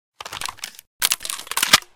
chamber.ogg